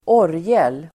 Ladda ner uttalet
orgel substantiv, organ Uttal: [²'år:jel] Böjningar: orgeln, orglar Definition: musikinstrument där ljudet uppstår genom att luft pressas genom pipor av olika längd Sammansättningar: orgel|spel (organ playing)